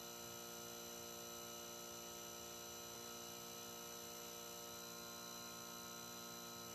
Sound Buttons: Sound Buttons View : The Backrooms Ambience
The-Backrooms-Ambience.mp3